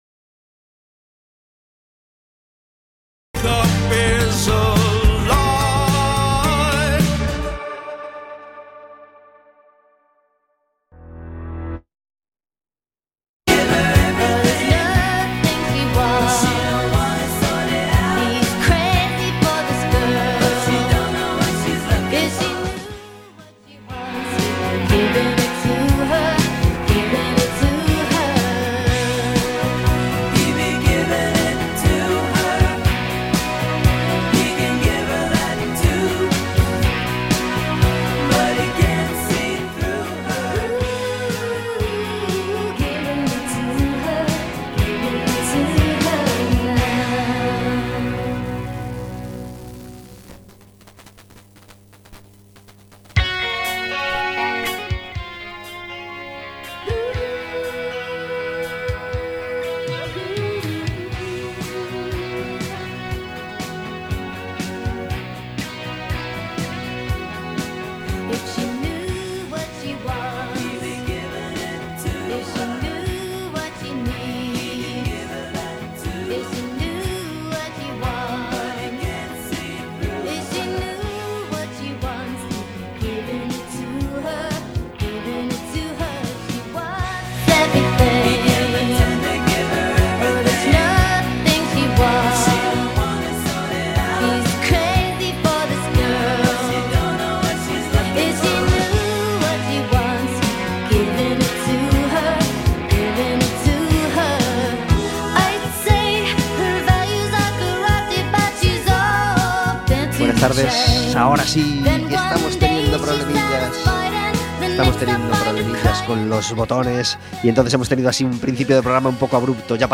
Un invitado cada día